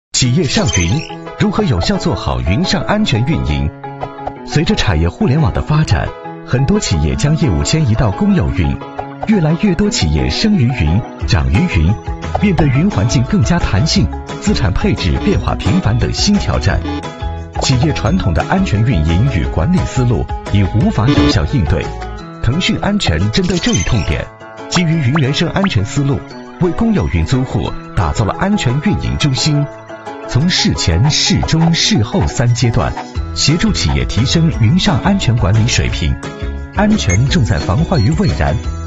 A男15号